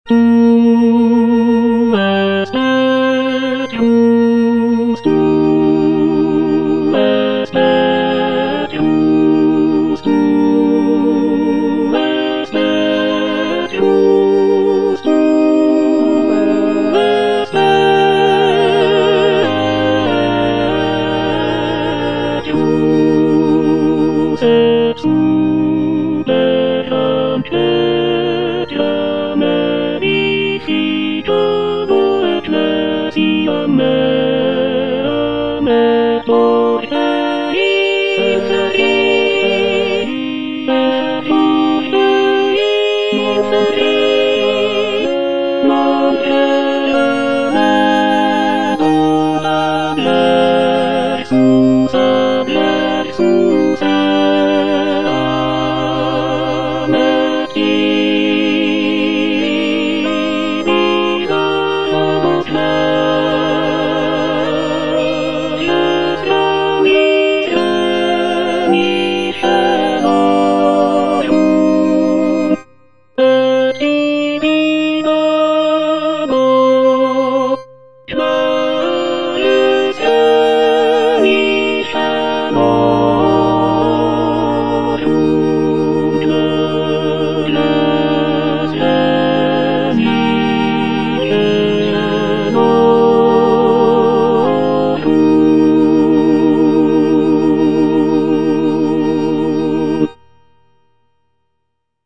L. PEROSI - TU ES PETRUS Tenor I (Emphasised voice and other voices) Ads stop:
"Tu es Petrus" is a sacred choral composition by Lorenzo Perosi, an Italian composer of the late 19th and early 20th centuries.
"Tu es Petrus" is a powerful and dramatic piece, often performed during papal ceremonies.